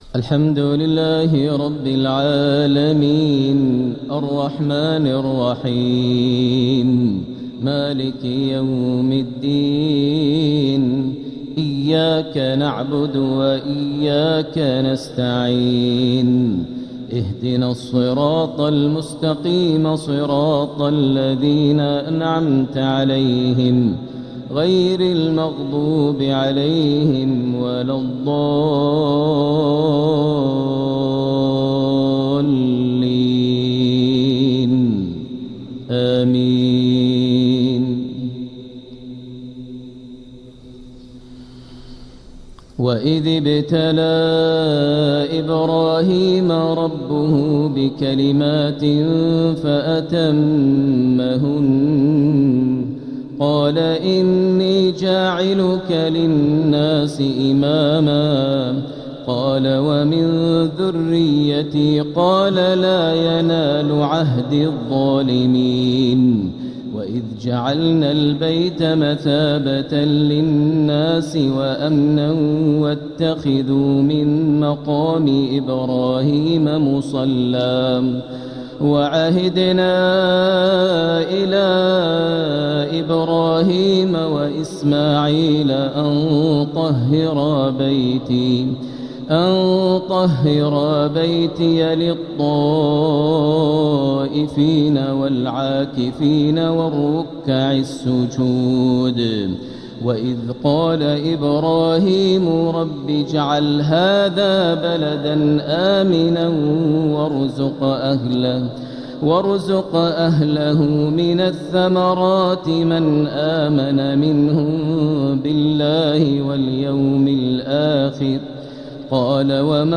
الإصدار القرآني المميز | لفروض شهر ربيع الآخر لعام 1447هـ | لفضيلة الشيخ د. ماهر المعيقلي > سلسلة الإصدارات القرآنية للشيخ ماهر المعيقلي > الإصدارات الشهرية لتلاوات الحرم المكي 🕋 ( مميز ) > المزيد - تلاوات الحرمين